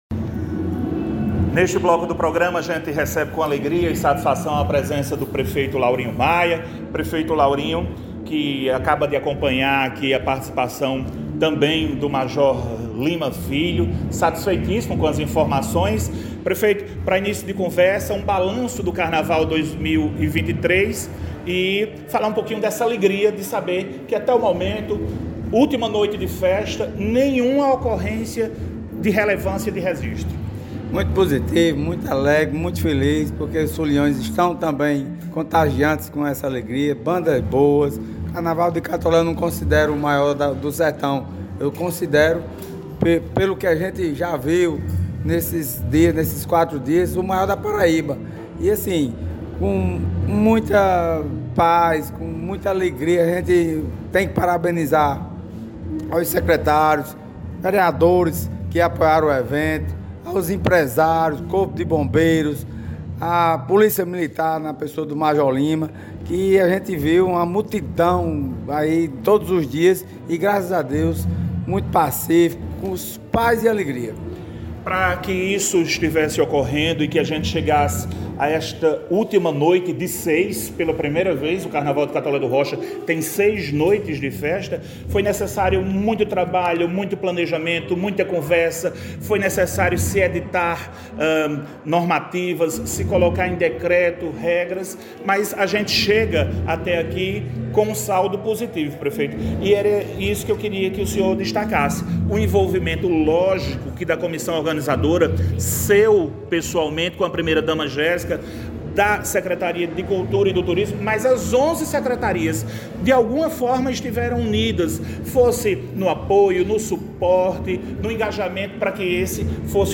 A avaliação é do prefeito Laurinho Maia que, em entrevista à imprensa, fez questão de ressaltar a importância do planejamento estratégico do evento, realizado com o apoio do Governo da Paraíba (12° Batalhão da Polícia Militar e Companhia do Corpo de Bombeiros), Ministério Público Estadual e Comissão Organizadora.
Laurinho-CARNAVAL-entrevista.mp3